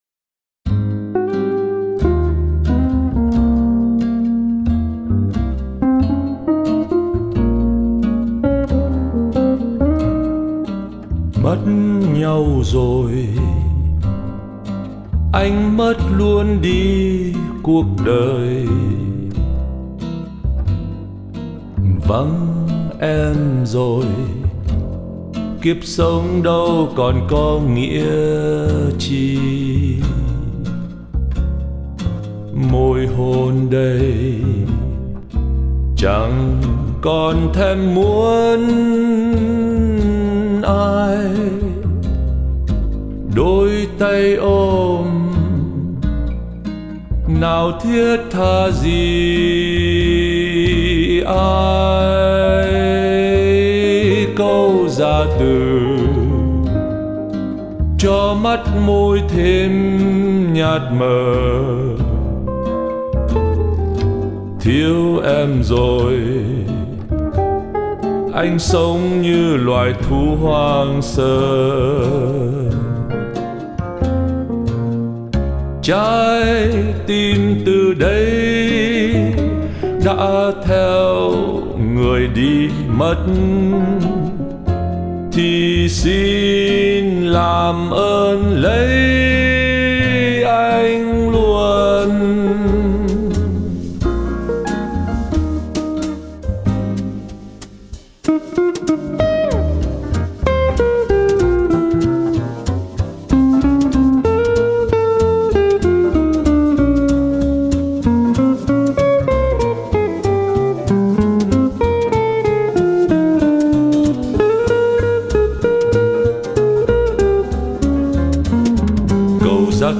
Một bài nhạc jazz kinh điển.